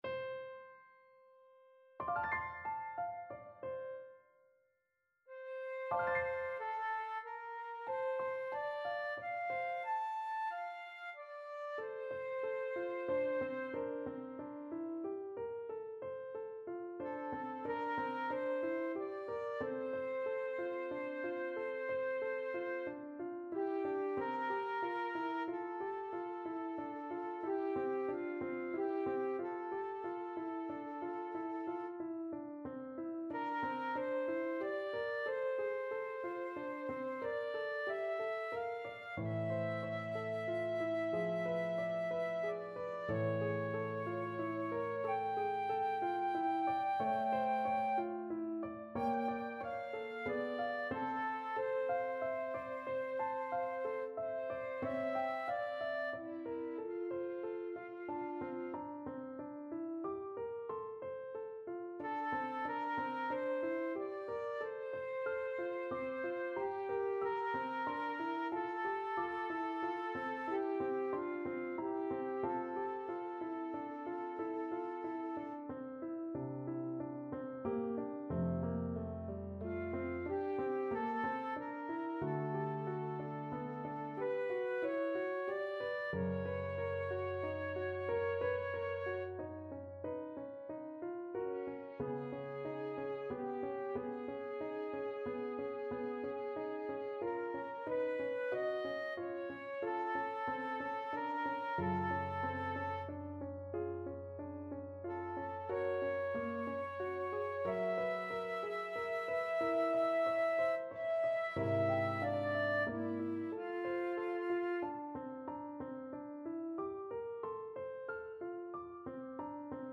Flute version
6/4 (View more 6/4 Music)
Lento =92
Classical (View more Classical Flute Music)